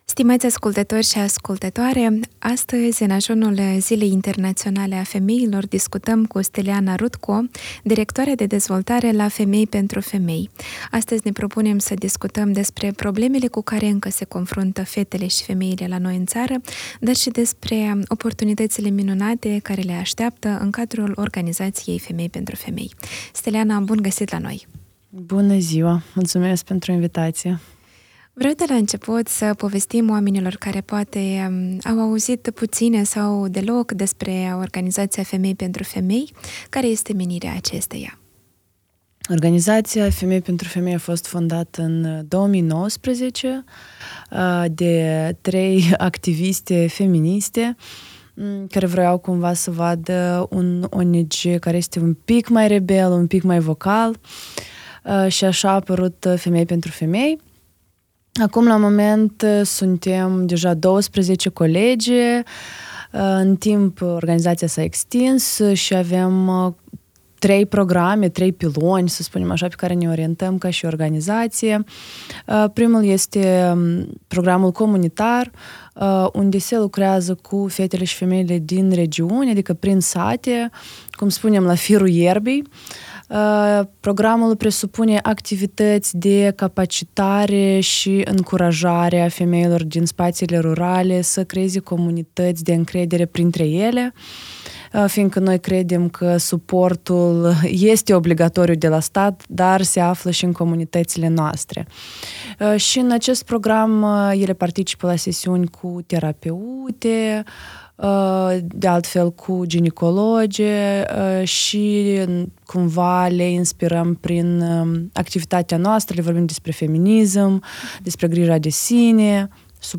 INTERVIU-MARS-FEMINIST.mp3